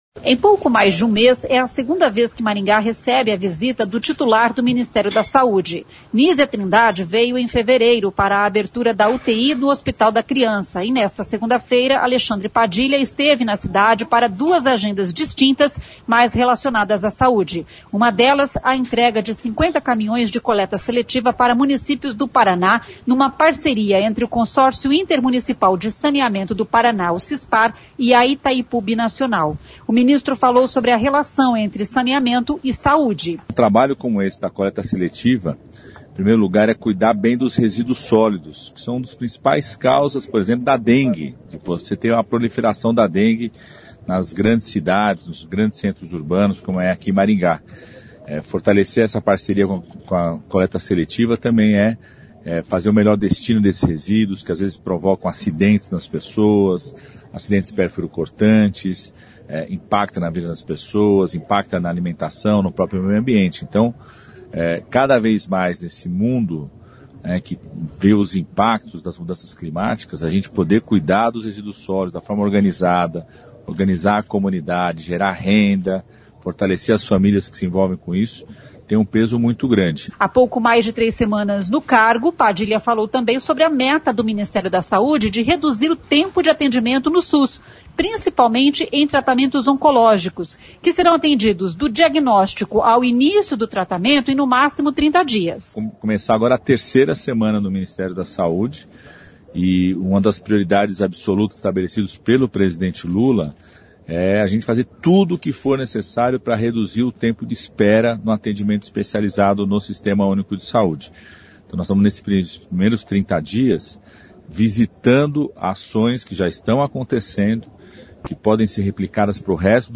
O ministro falou sobre a relação entre saneamento e saúde.